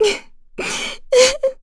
Laudia-Vox_Sad_kr.wav